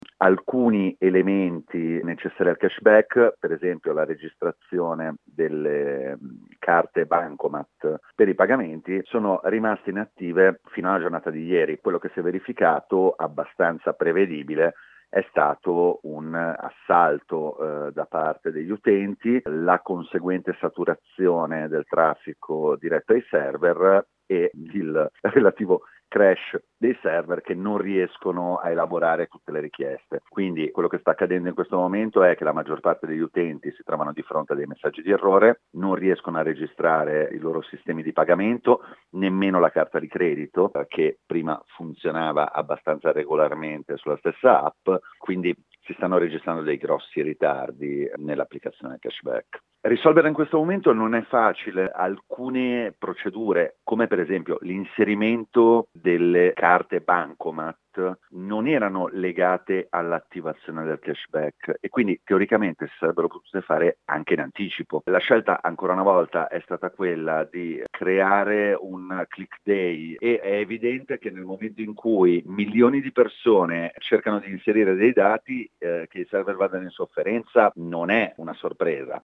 Il racconto della giornata di martedì 8 dicembre 2020 attraverso le notizie principali del giornale radio delle 19.30, dai dati dell’epidemia in Italia al voto di domani sulla riforma del Mes che fa tremare il governo.